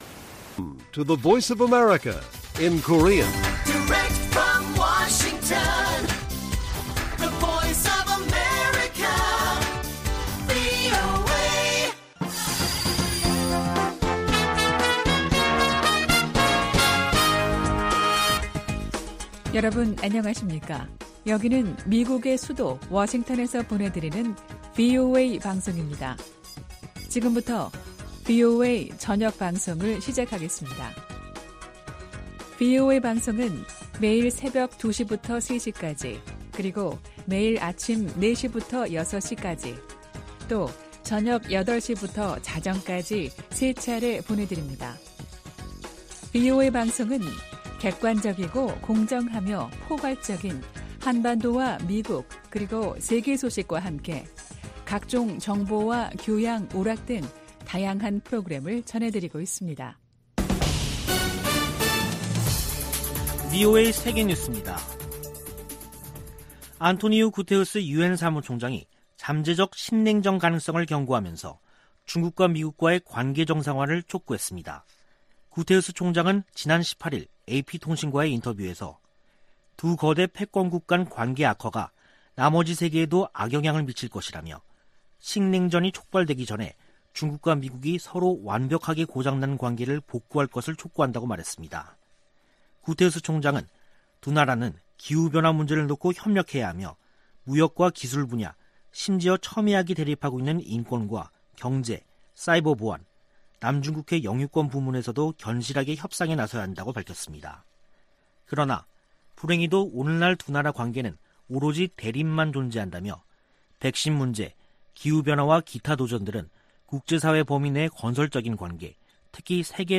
VOA 한국어 간판 뉴스 프로그램 '뉴스 투데이', 2021년 9월 20일 1부 방송입니다. 유엔주재 미국 대사가 북한의 최근 미사일 발사 관련 사안을 대북제재위원회에서도 논의할 것이라고 밝혔습니다. 존 하이튼 미 합참의장은 북한이 미사일 역량을 빠른 속도로 발전시켰다고 17일 말했습니다. 열차를 이용한 북한의 탄도미사일 시험발사는 선제적 대응을 어렵게할 것이라고 미국의 군사 전문가들이 분석했습니다.